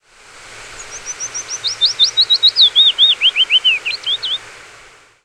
File:Phylloscopus rochilus.ogg
English: A Willow Warbler (Phylloscopus trochilus) singing in a birch tree in Southern Finland.